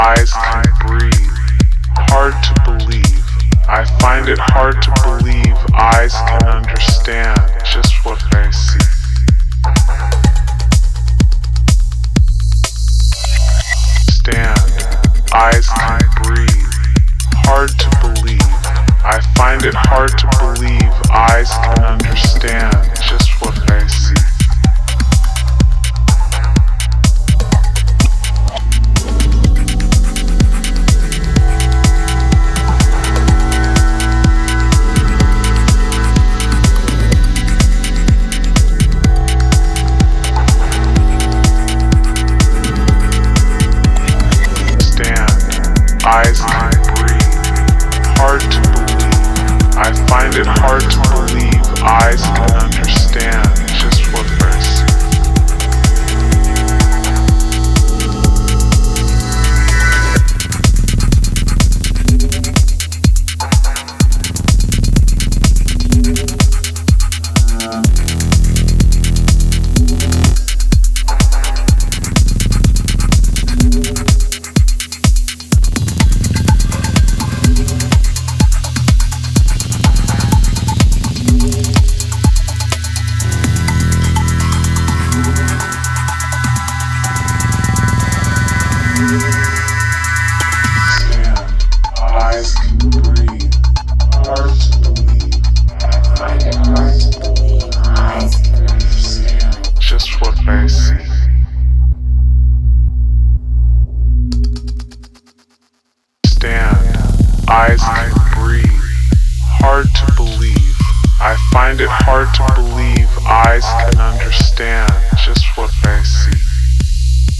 4 intricate signals for late-night movement.